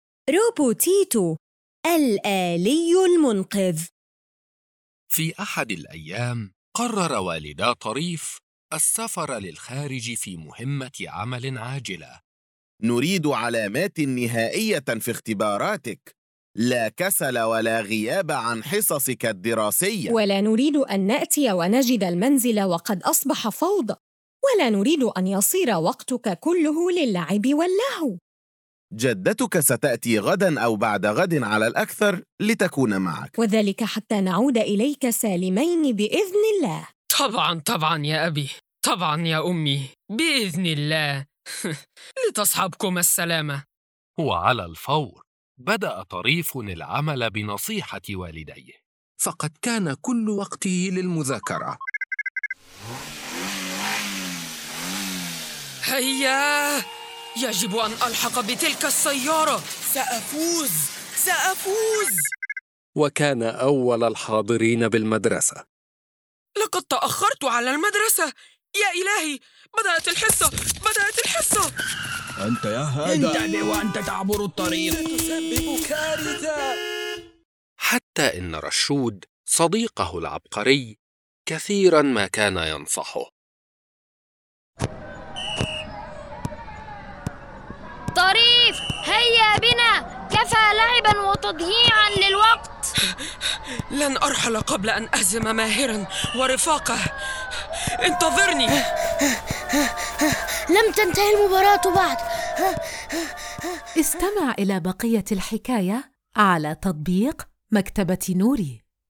كل قصة يتم تقديمها بصوت جذاب جميل، مع مؤثرات لتحفيز الطفل على التفاعل والاندماج في عالم الحكايات.